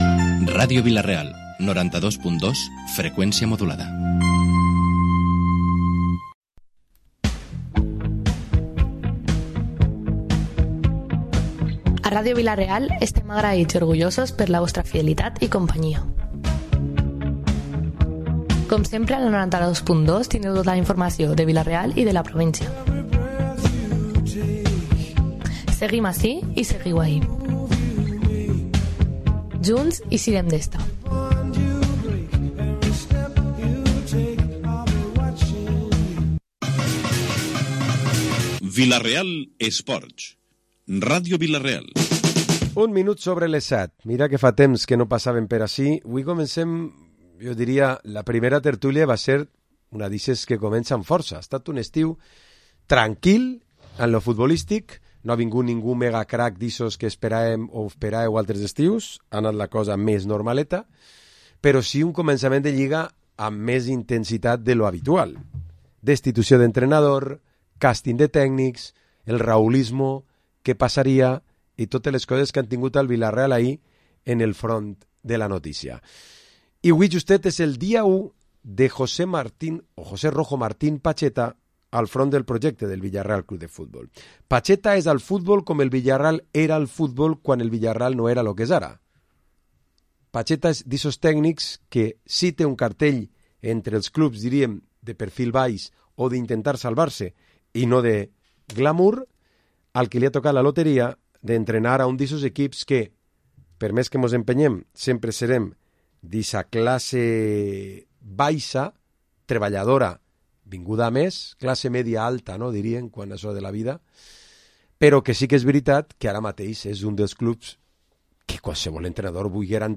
Programa esports tertúlia dilluns 11 de Setembre